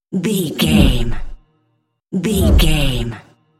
Deep whoosh fast pass by 969
Sound Effects
dark
intense
whoosh